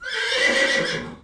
horsesqueal.wav